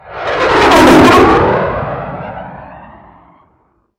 Jet Flyover
A powerful jet aircraft passing overhead with full Doppler shift from approach to fade
jet-flyover.mp3